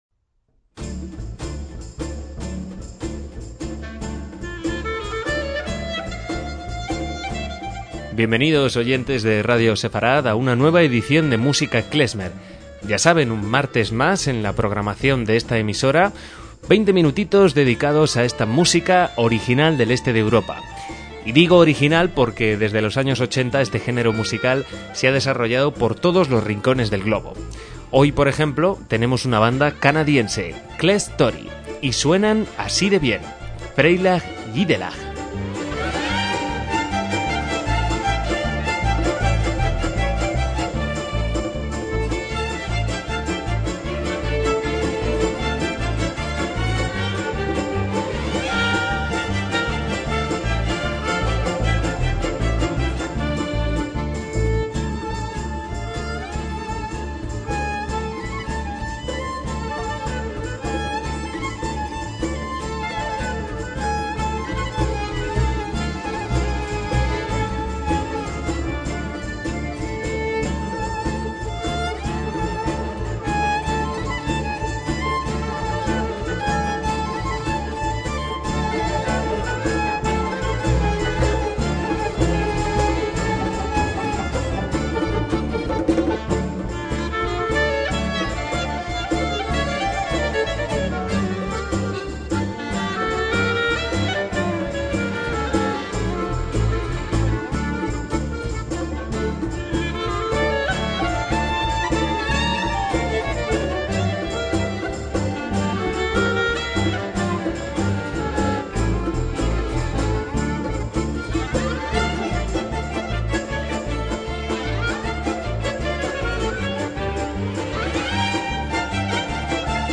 MÚSICA KLEZMER
clarinetes
violín y viola
contrabajo
acordeón
guitarra
piano